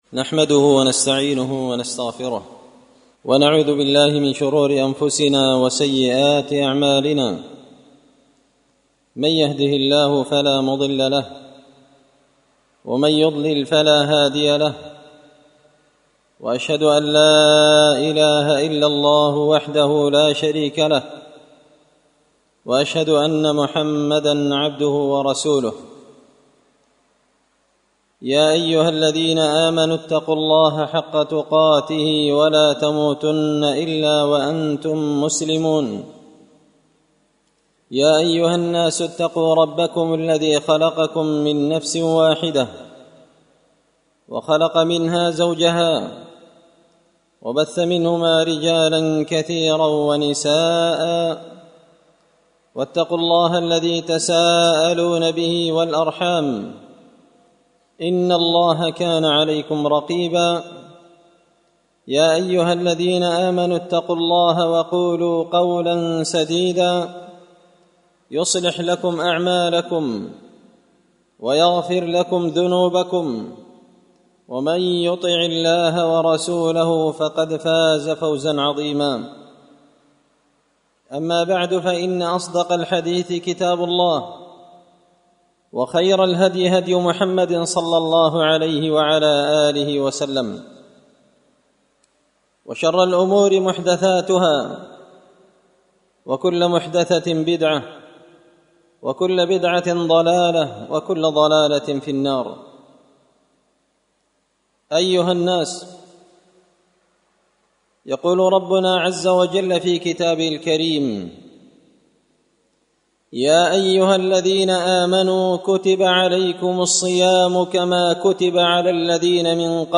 خطبة جمعة بعنوان – من احكام الصيام
دار الحديث بمسجد الفرقان ـ قشن ـ المهرة ـ اليمن